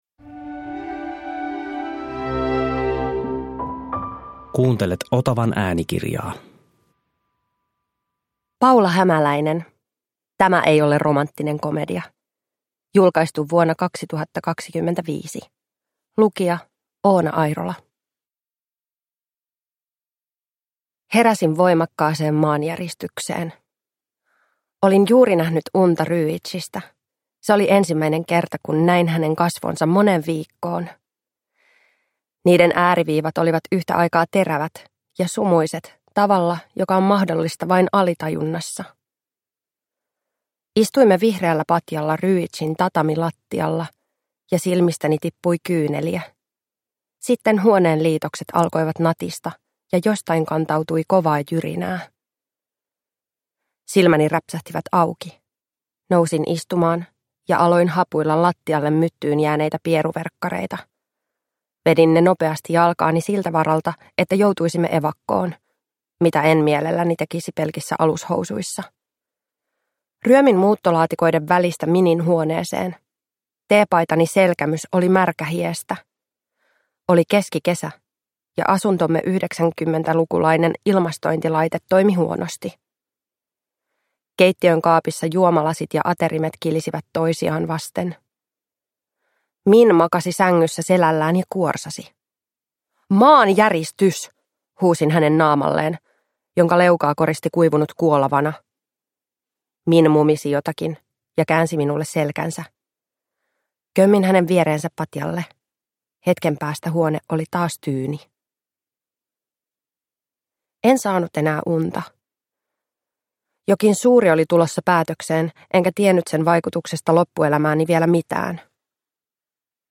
Tämä ei ole romanttinen komedia – Ljudbok